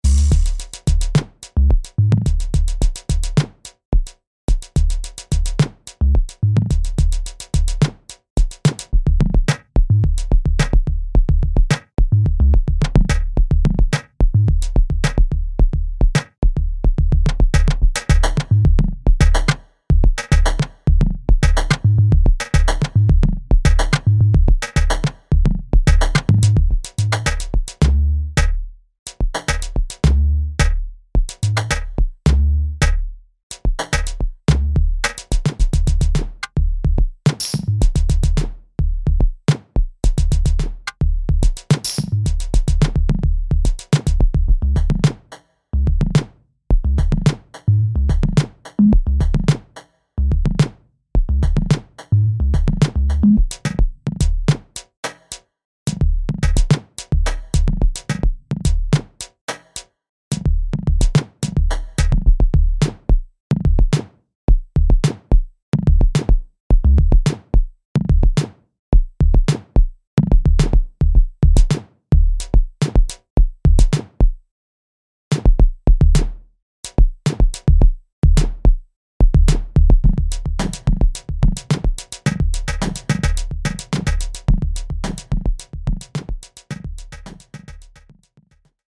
这套音色库包含大量变调嗵鼓和极具冲击力的合成打击乐，为您的音乐作品带来独特的音色和风味。